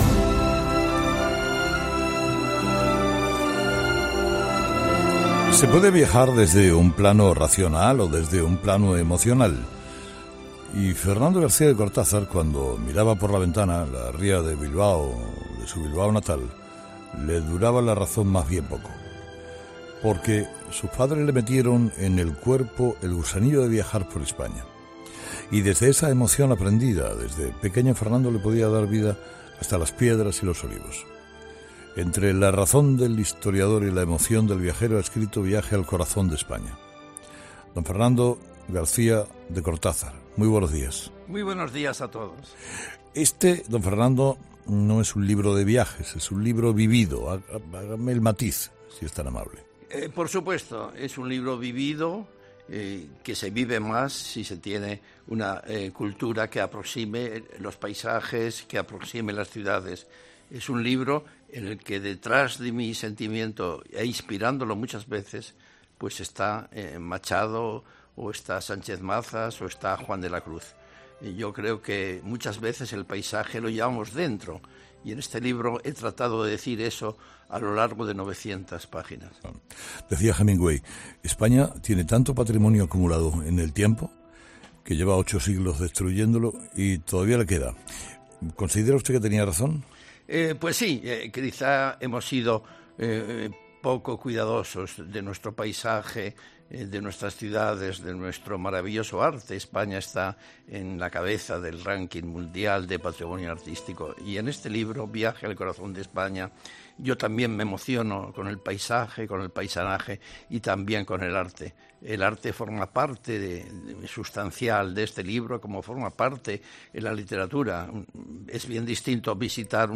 Escucha la entrevista a Fernando García de Cortázar en Herrera en COPE